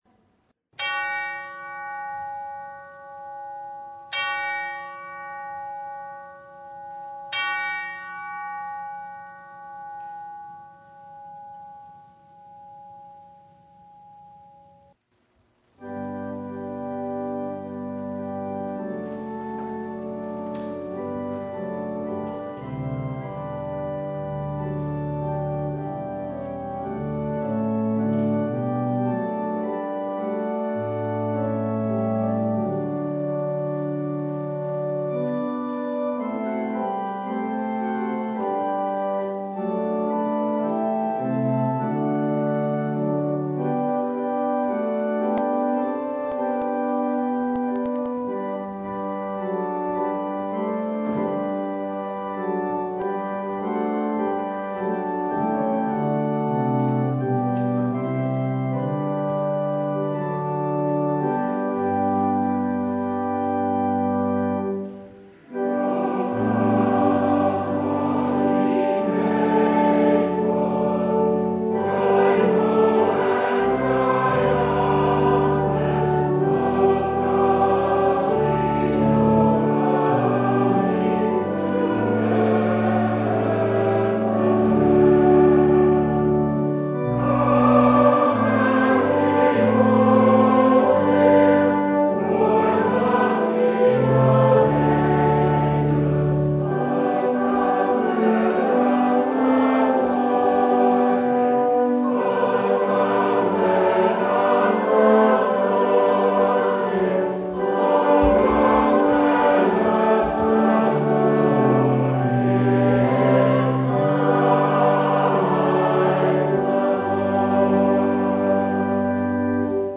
Passage: John 1:1-14 Service Type: Christmas Day
Full Service